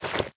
equip_chain2.ogg